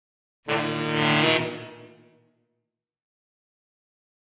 Saxophone
Horn Section Criminal Increasing 6 Lower Type D